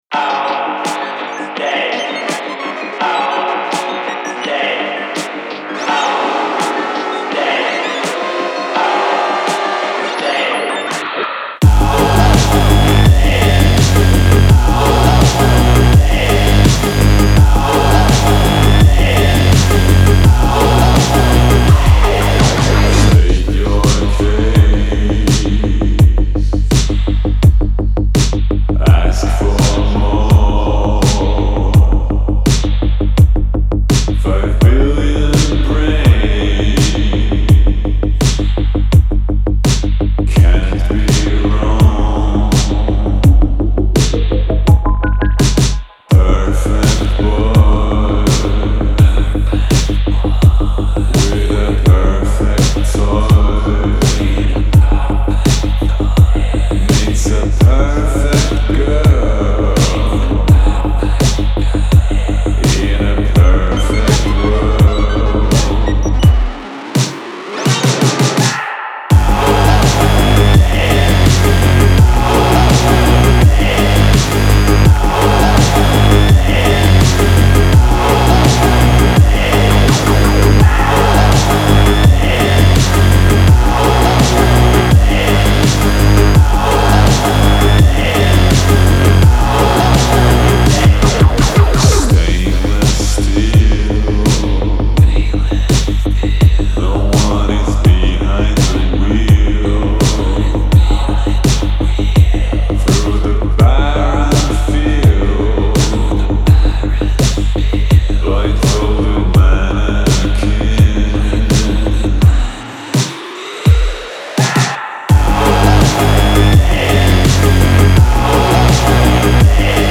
• Жанр: Dance, Electronic